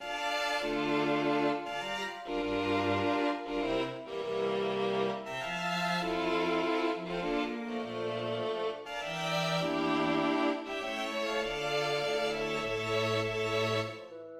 Handel, aria "Pena tiranna" from Amadigi, orchestral introduction